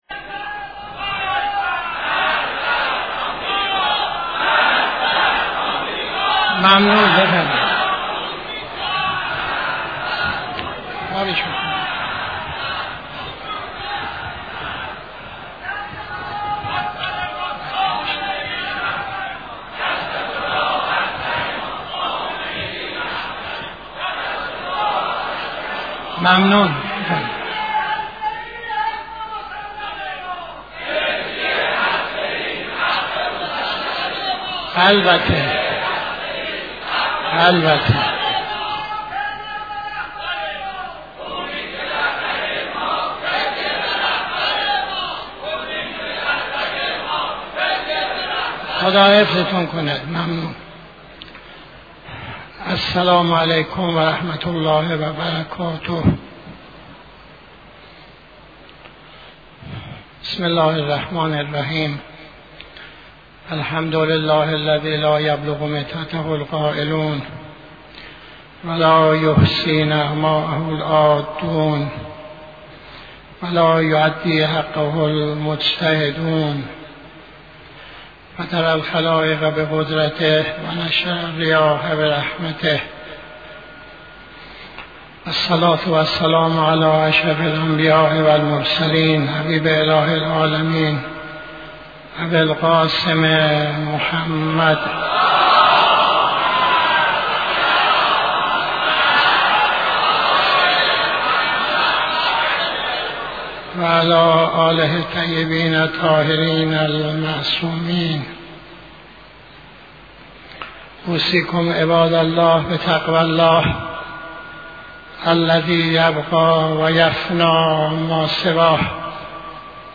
خطبه اول نماز جمعه 02-04-85